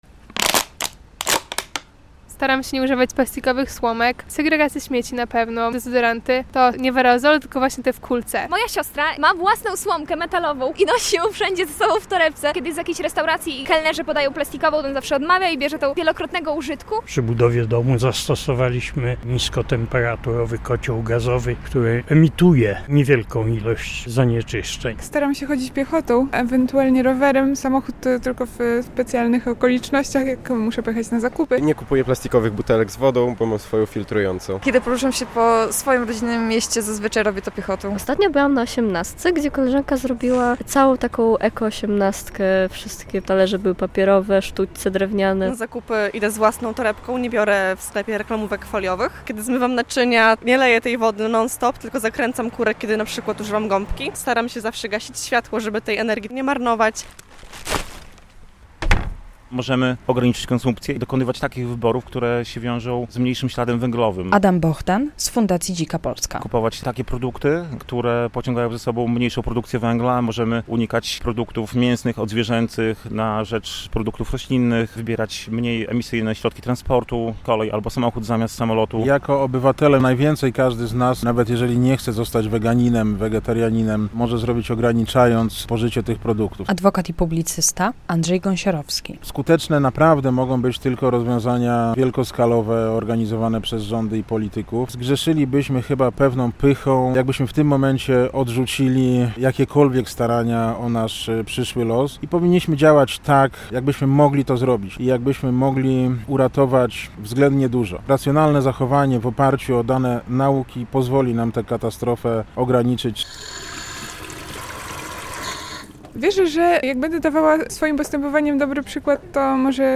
pytała białostoczan - co robią, aby chronić środowisko?